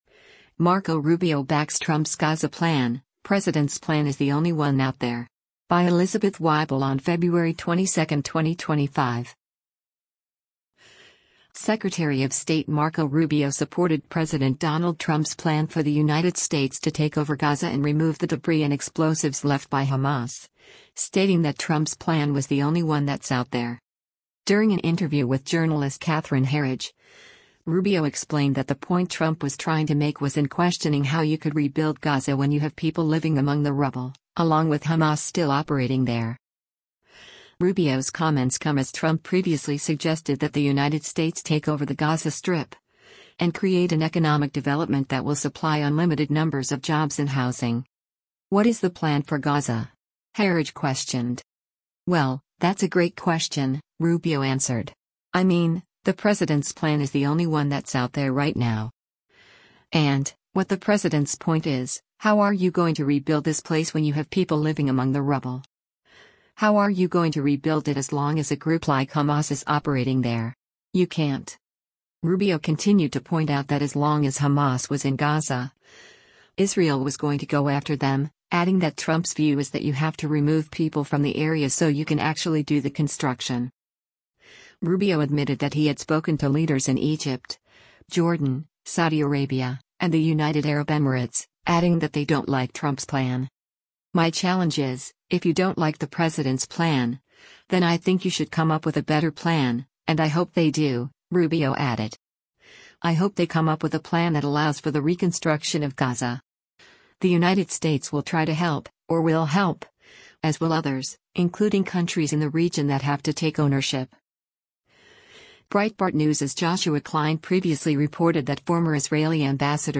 During an interview with journalist Catherine Herridge, Rubio explained that the point Trump was trying to make was in questioning how you could rebuild Gaza “when you have people living among the rubble,” along with Hamas still operating there.